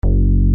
bass_synt_analog.mp3